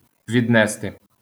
vìdnesti
wymowa: